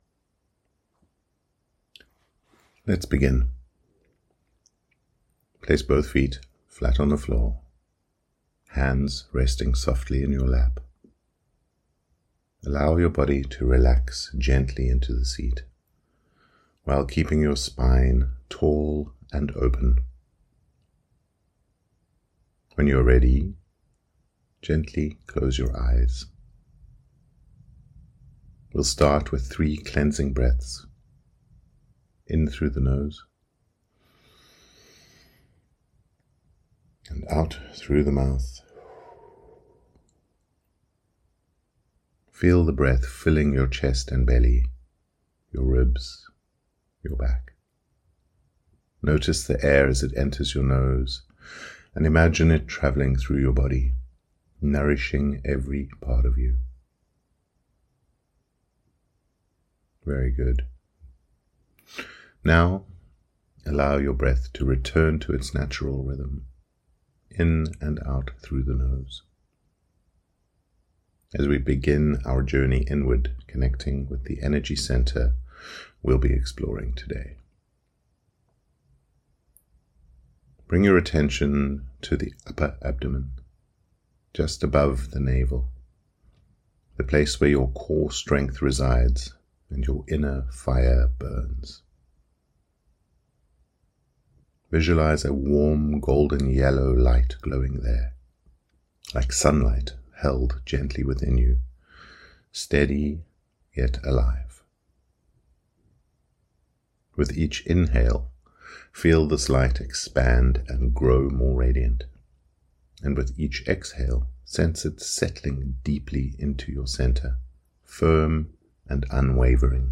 Solar Plexus Chakra Meditation
CH03-meditation.mp3